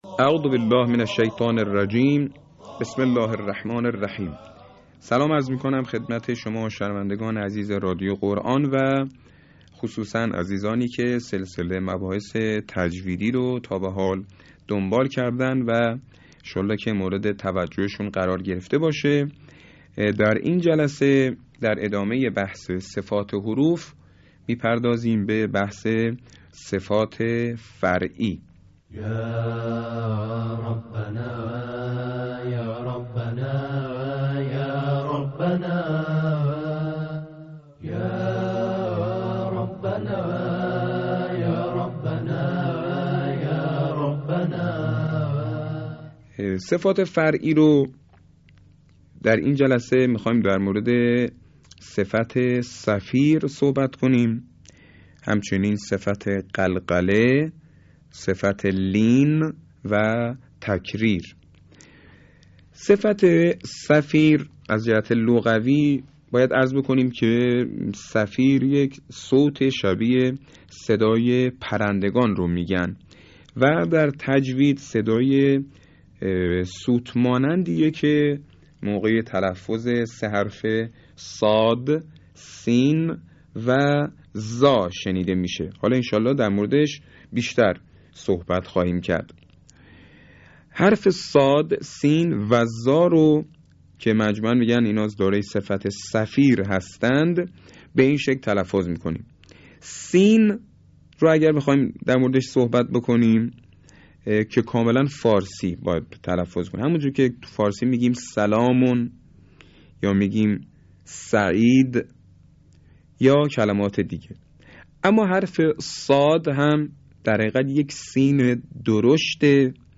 صوت | آموزش تجویدی صفات حروف